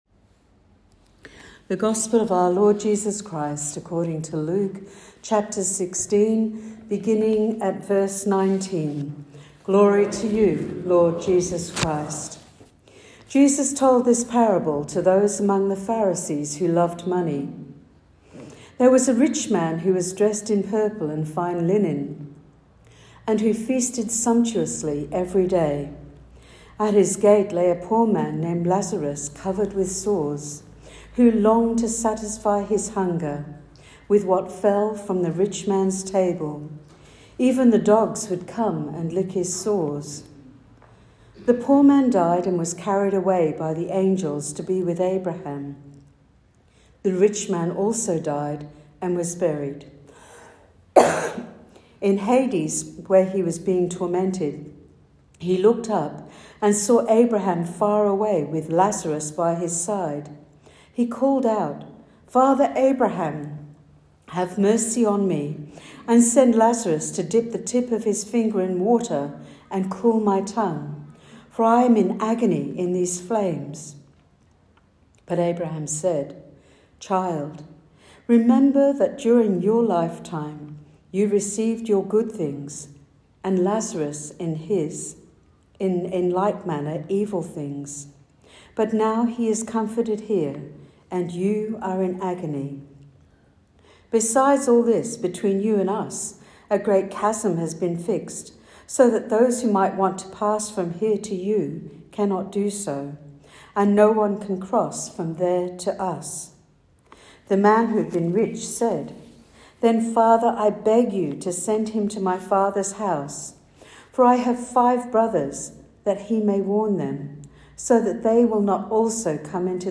Weekly Church Service – Pentecost 16: 28 September 2025 – St John's Anglican Church Greenwood
Weekly Church Service – Pentecost 16: 28 September 2025